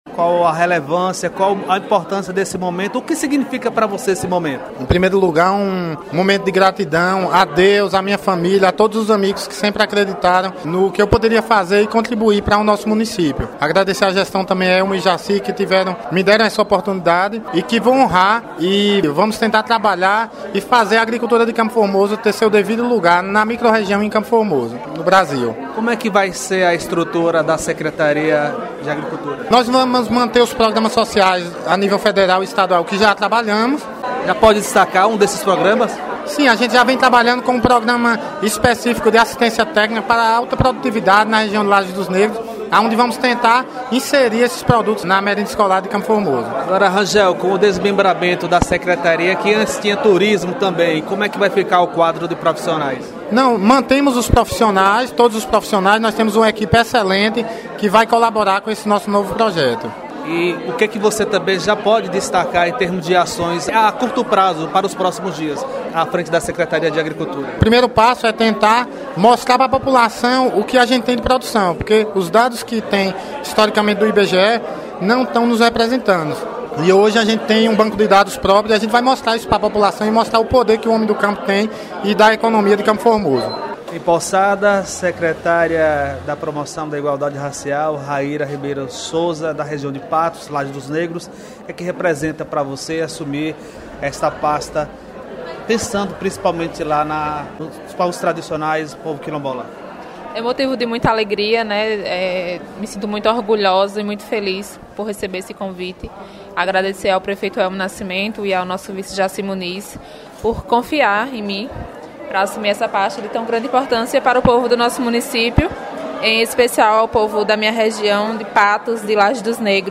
Reportagem: Secretários Rangel, Raíra secretária da promoção da igualdade racial, e Rômulo Filho